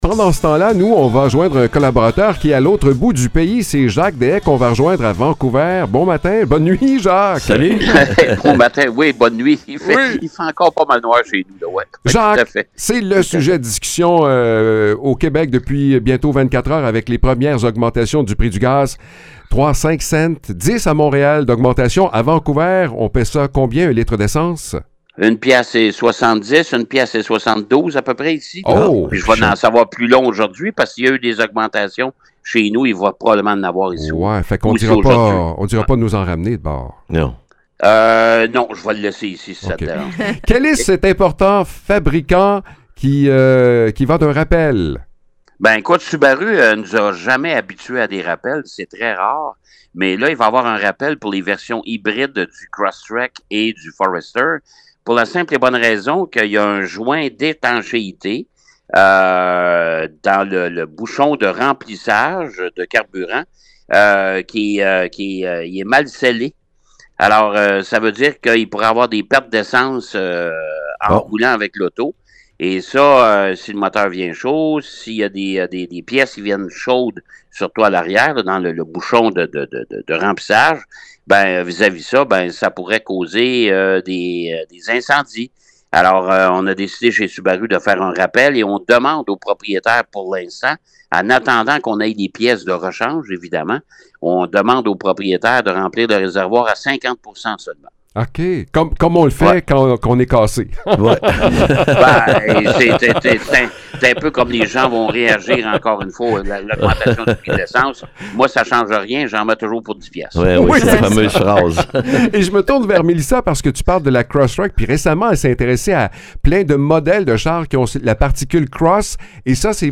Le chroniqueur automobile
est en direct de Vancouver pour mettre à l’essai un tout nouveau modèle de Mitsubishi. Il en profite aussi pour nous parler d’un rappel surprenant chez Subaru, une situation plutôt inhabituelle pour ce constructeur reconnu pour sa fiabilité.